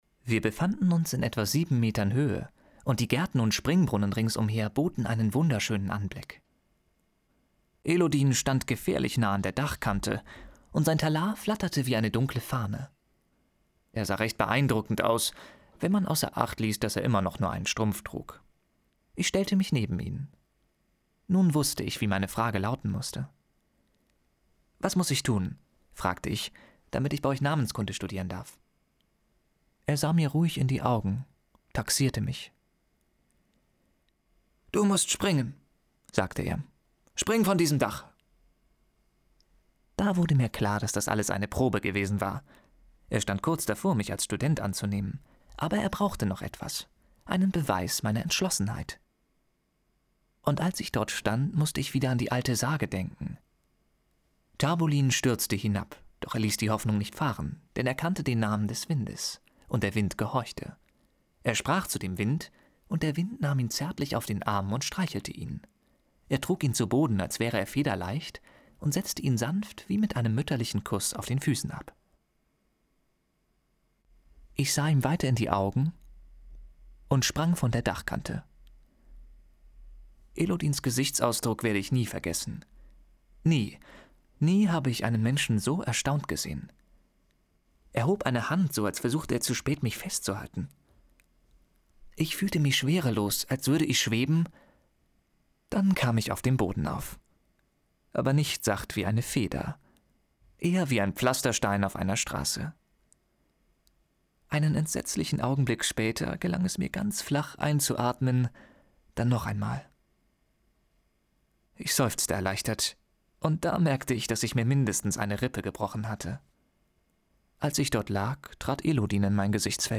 Der Name des Windes Hörprobe (unbearbeitet)_01